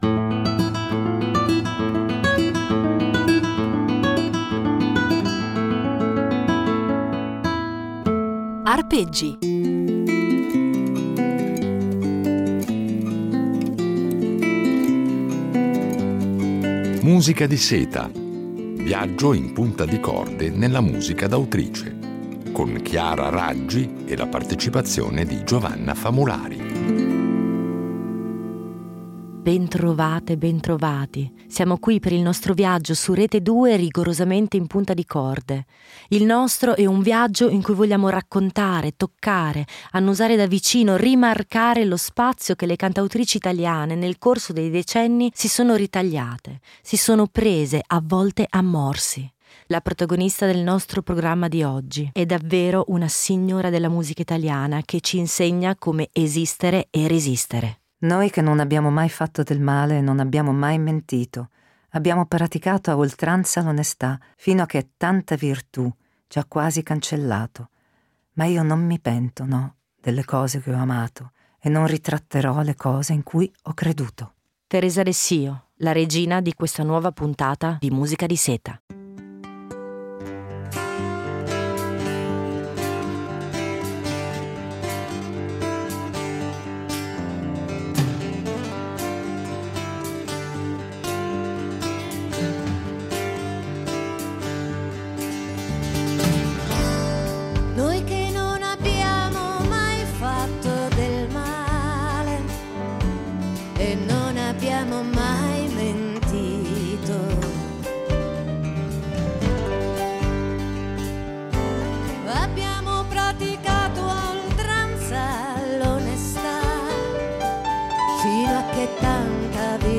Una serie di Arpeggi impreziosita dalle riletture originali di un duo, ancora inedito, con la violoncellista